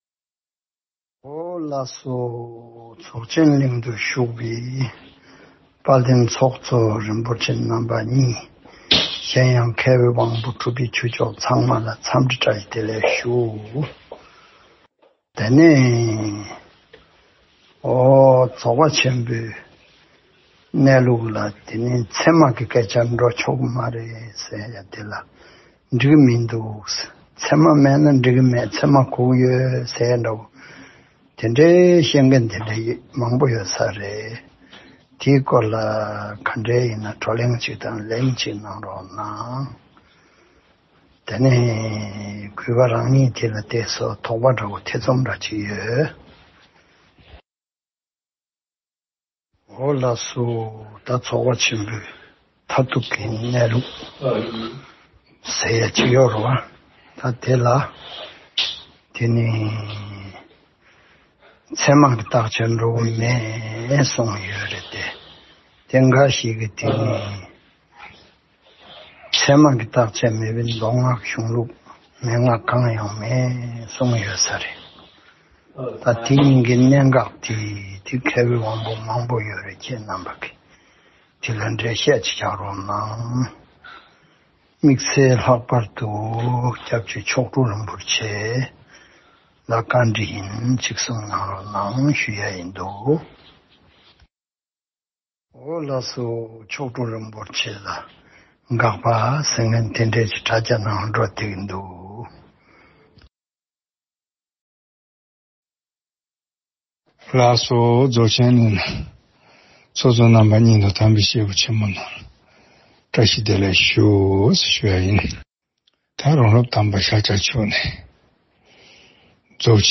བཀའ་སློབ།